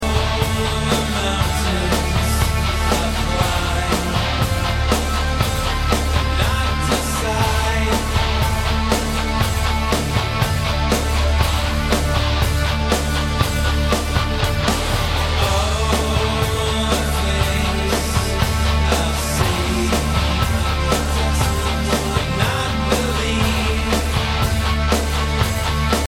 该作品音质清晰、流畅，源文件无广告